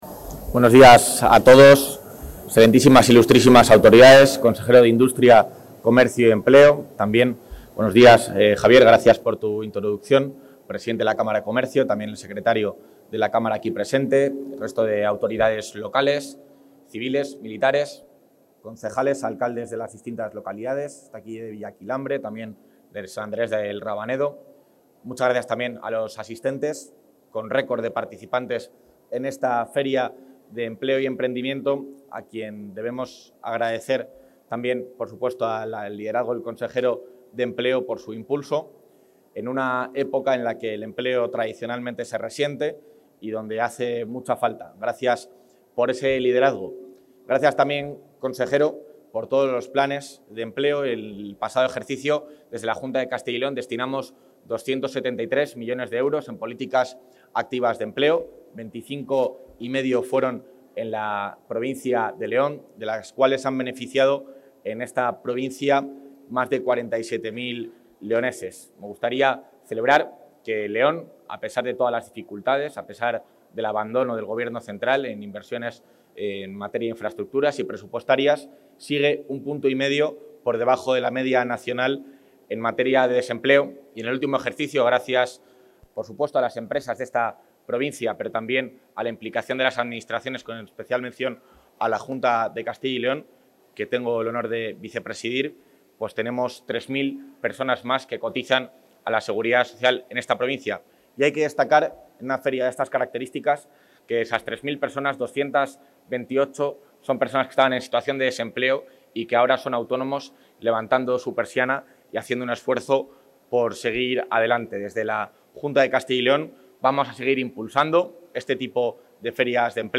El vicepresidente de la Junta, Juan Garcia-Gallardo, inauguró esta mañana la VI Feria de Empleo y Emprendimiento de León, que ha...
Intervención del vicepresidente de la Junta.